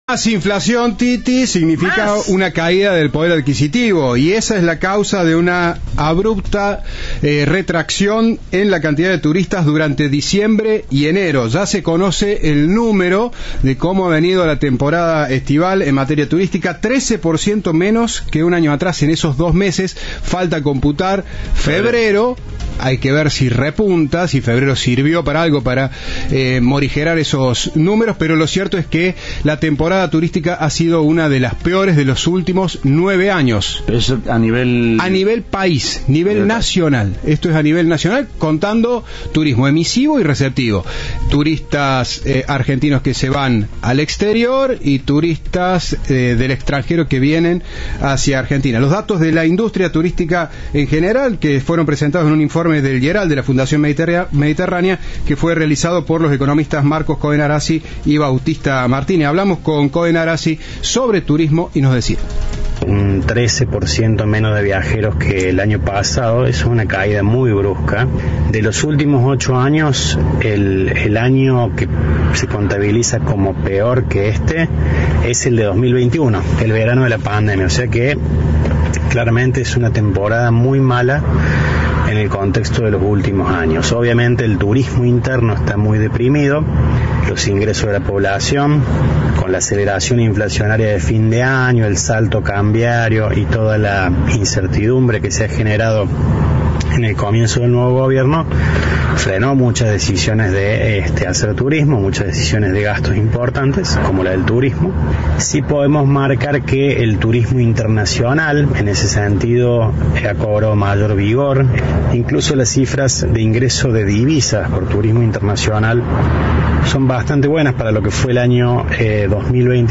En diálogo con Cadena 3
Informe